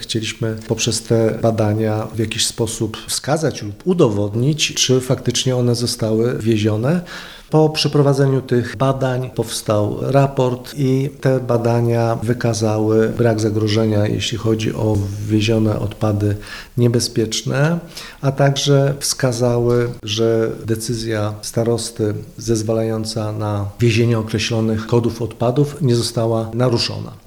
– Tymi badaniami chcieliśmy także uspokoić złe nastroje mieszkańców – powiedział Waldemar Wrześniak, wicestarosta nowosolski: